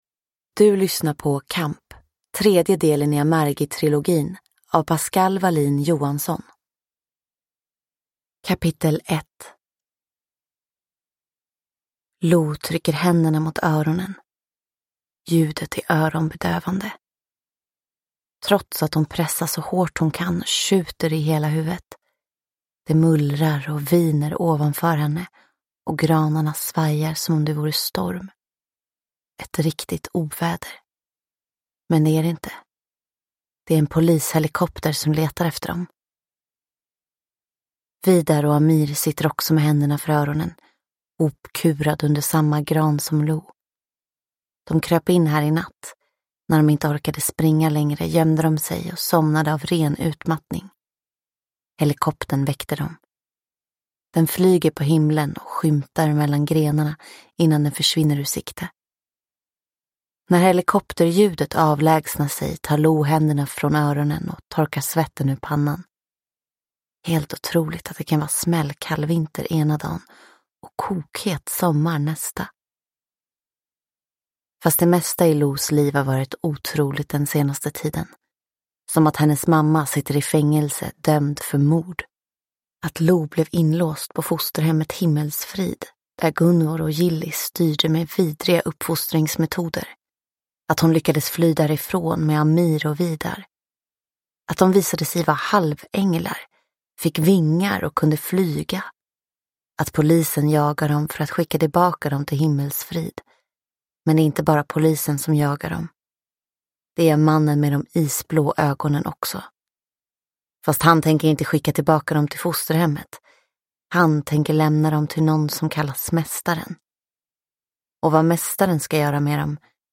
Kamp – Ljudbok – Laddas ner